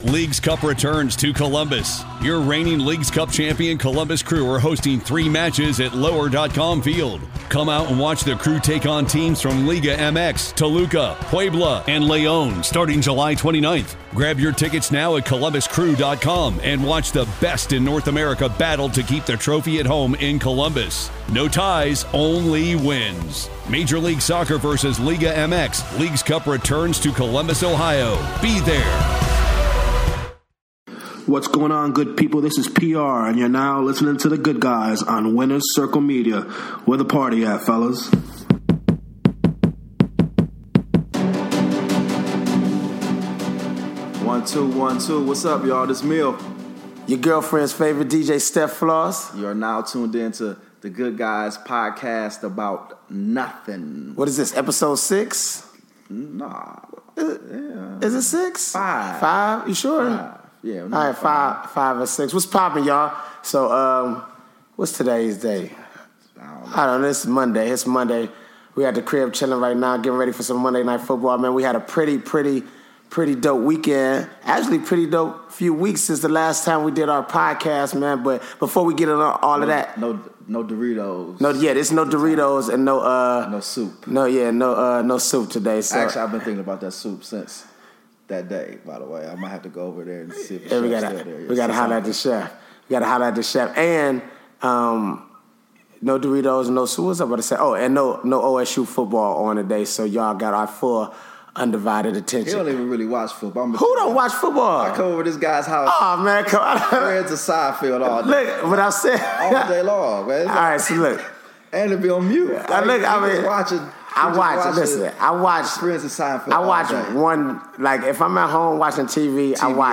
Episode 06: Bryson Tiller Interview